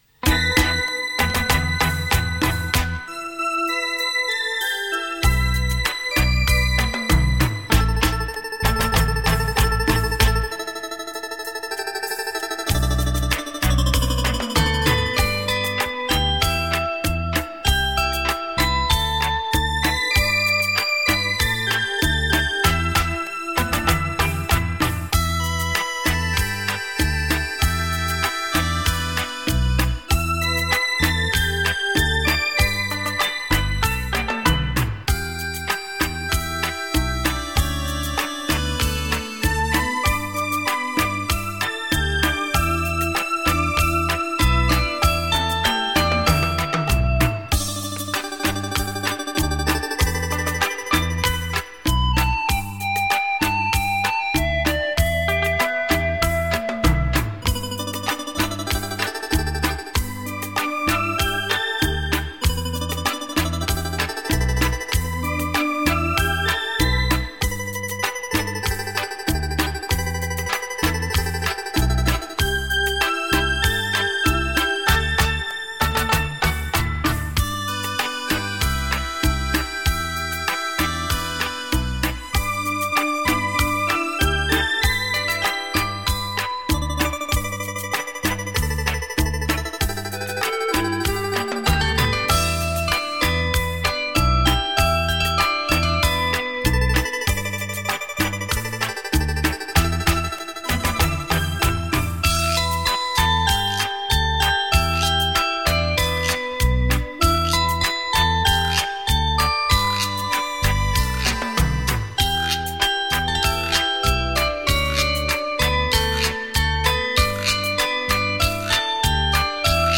双立体